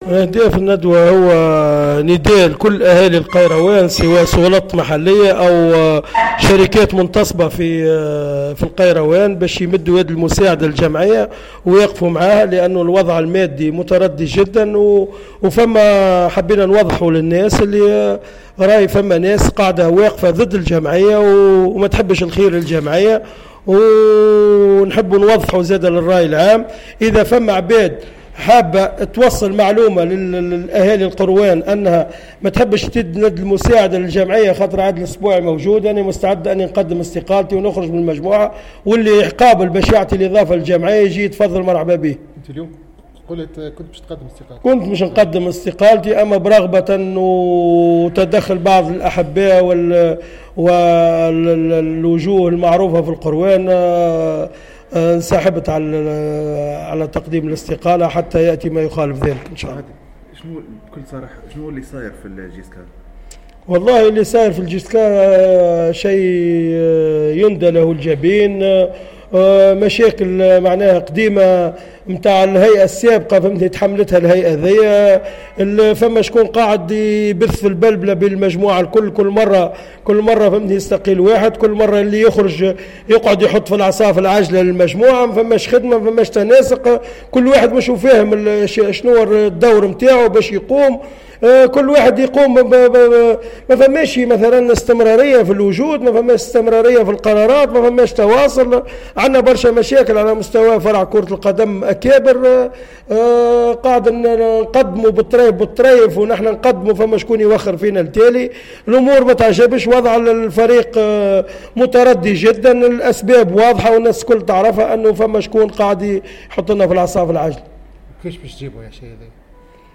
عقدت إدارة الشبيبة القيروانية ندوة صحفية